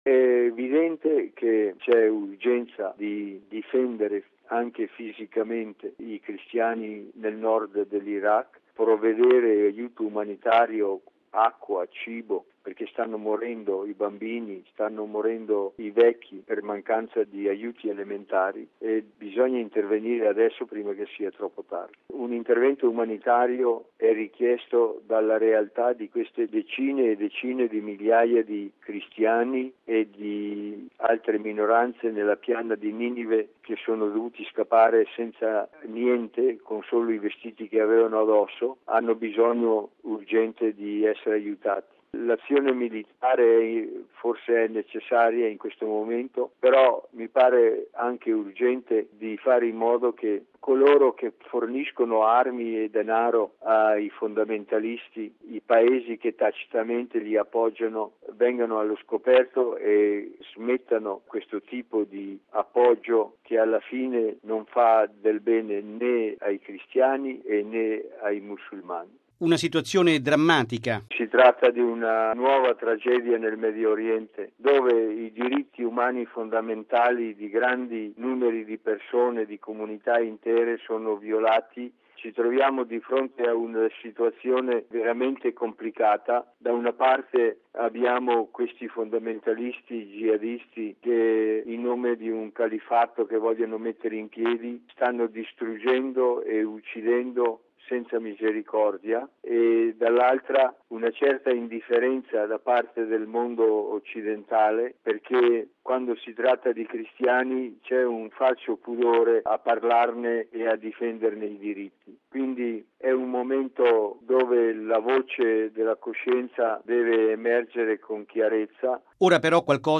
Sulla situazione delle migliaia di civili cacciati dalle loro città dai miliziani jihadisti, ascoltiamo l’arcivescovo Silvano Maria Tomasi, osservatore permanente della Santa Sede presso l’Ufficio Onu di Ginevra